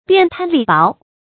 變貪厲薄 注音： ㄅㄧㄢˋ ㄊㄢ ㄌㄧˋ ㄅㄛˊ 讀音讀法： 意思解釋： 指改變、勸勉貪圖財利、行為輕薄的人使之廉潔忠厚。